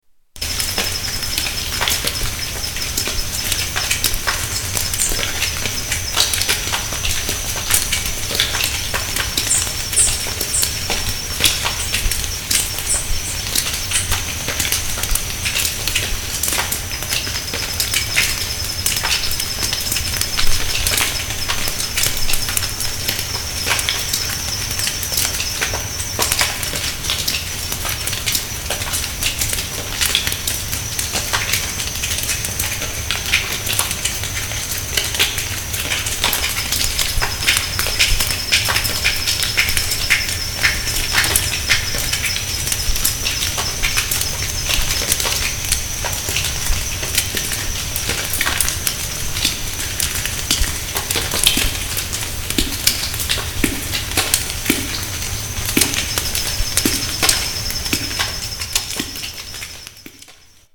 Small waterfall at of Chapada Diamantina